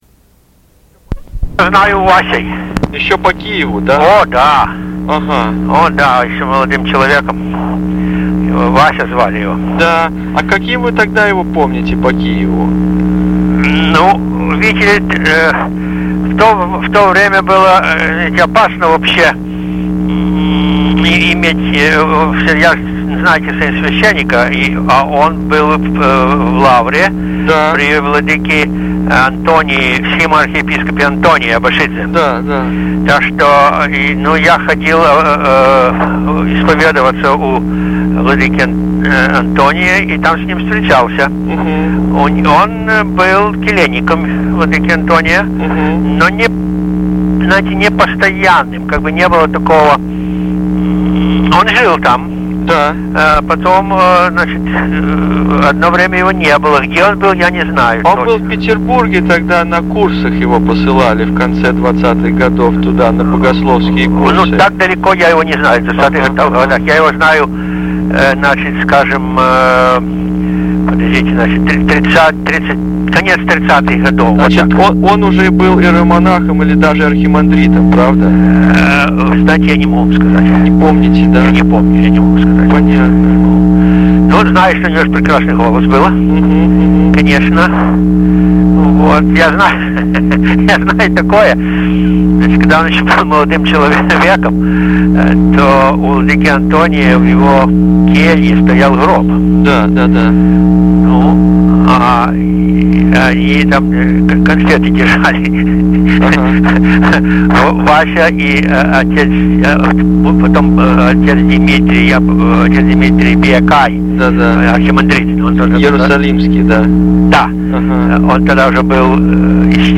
Здесь помещается звукозапись беседы и ее расшифровка